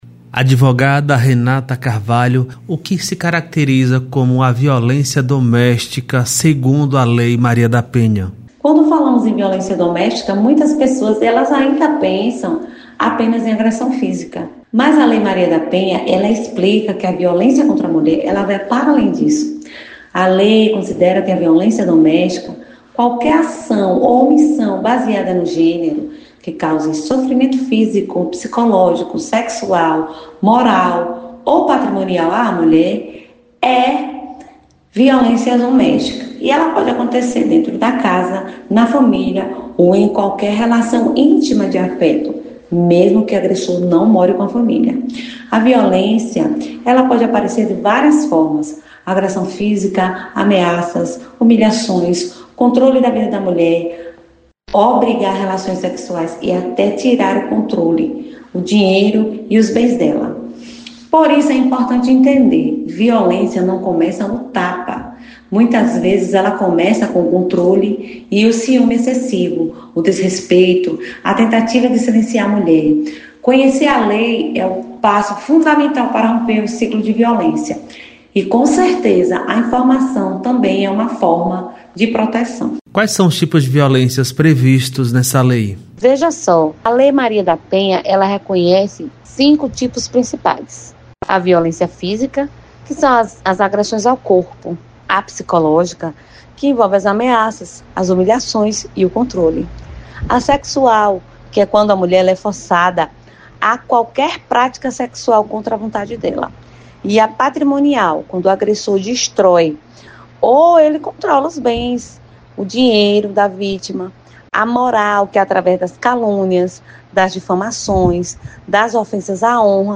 Advogada fala sobre violência contra a mulher e medidas protetivas